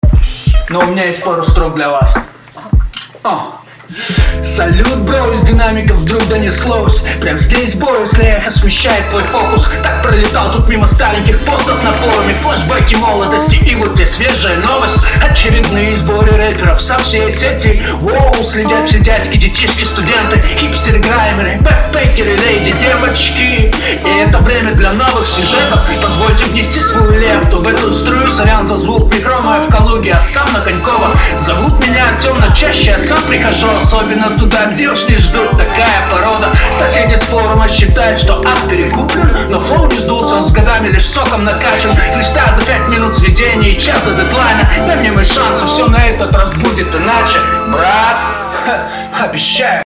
В твоём случае трек средний, но отсутствием элементарного качества ты убиваешь интерес к своей работе.